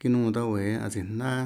The text was recorded with Audacity 1.3.9 using a Zoom H4n portable digital recorder connected to a MacBook Pro computer at a sampling rate of 44.1 KHz and a quantization of 16 bits—CD quality.